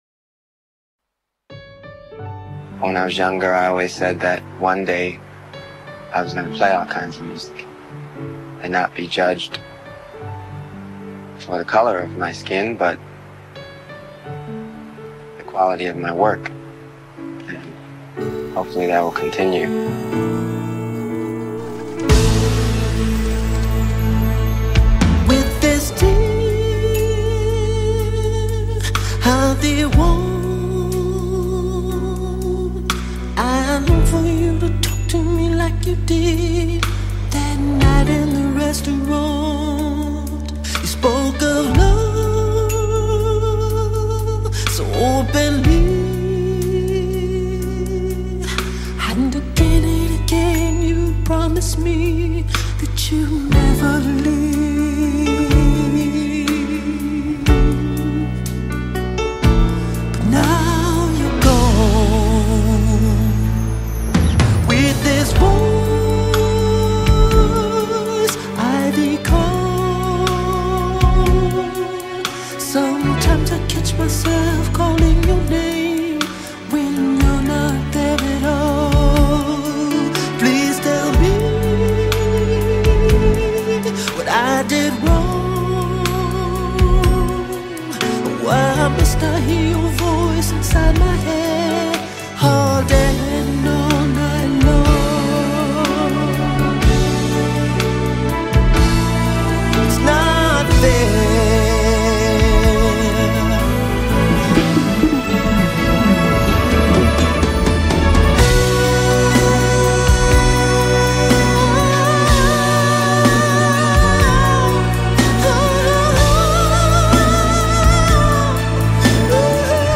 Talented vocalist